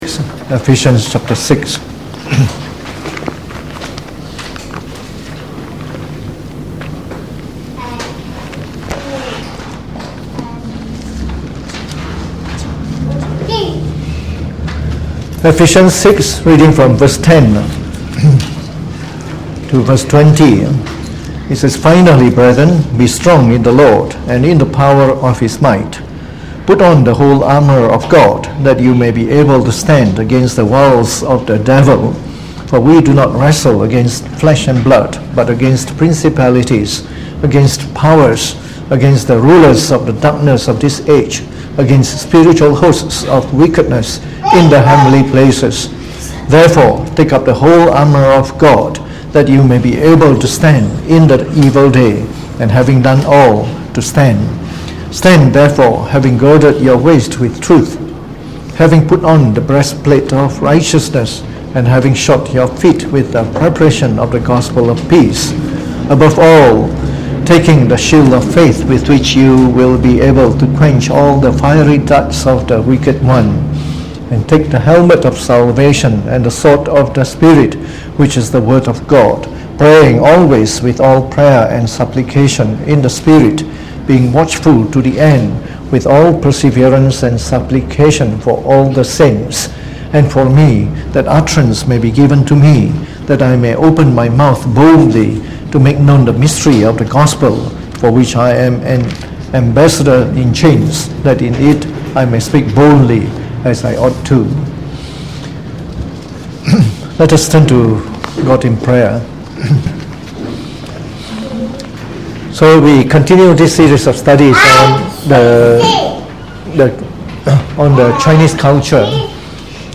Delivered on the 14th of August 2019 during the Bible Study, from the series on The Chinese Religion.